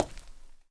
Index of /q3min/gamedata/sound/player/footsteps/
step3.wav